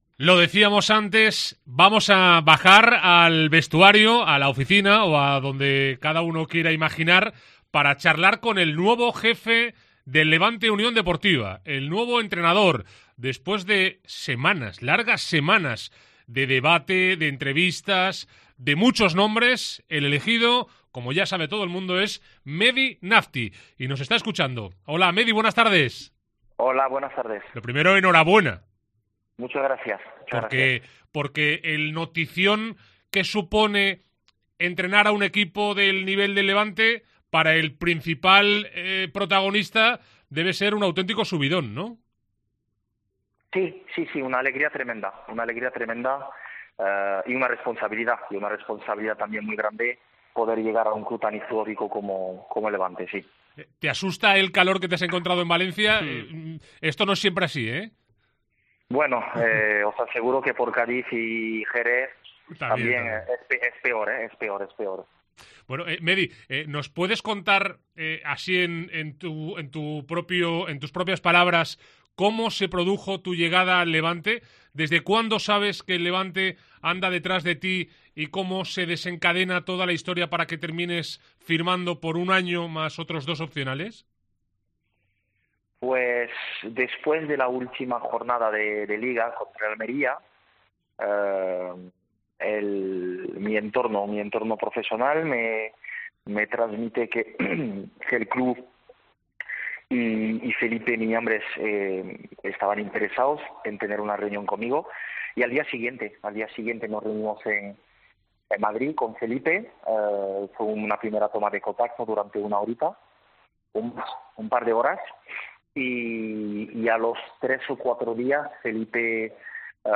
Entrevista en COPE con Nafti